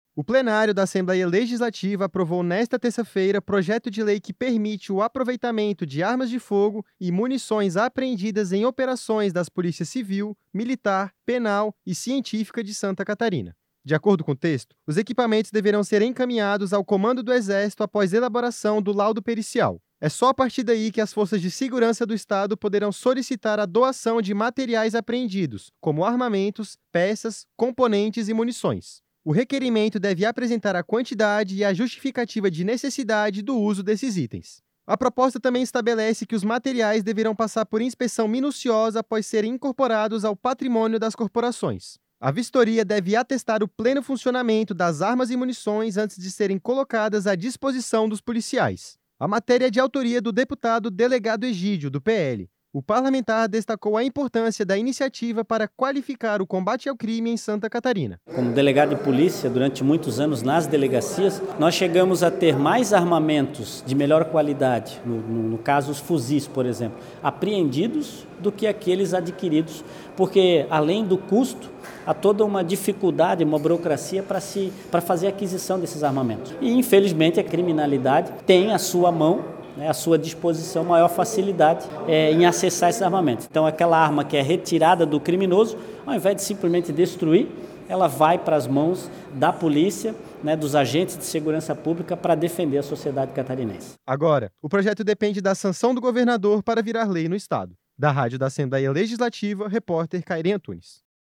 Entrevista com:
- deputado Delegado Egídio (PL), autor do projeto de lei.